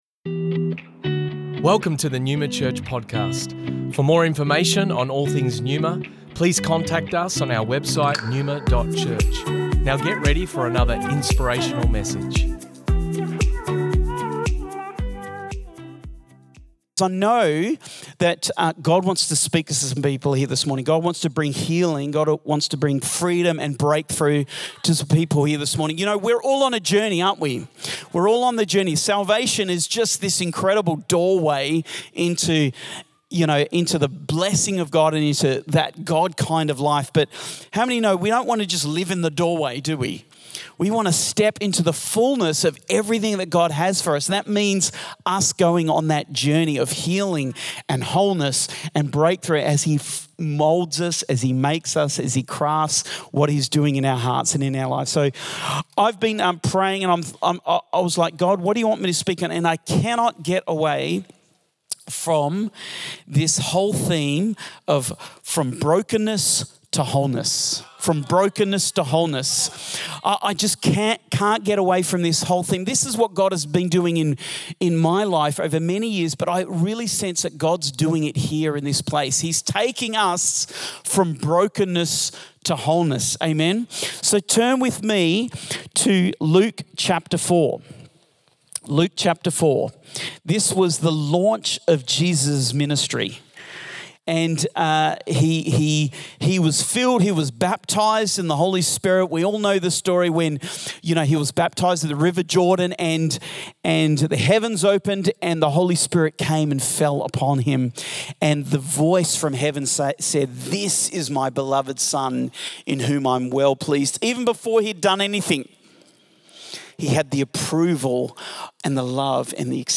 Originally recorded at Neuma Melbourne West On the 27th of August 2023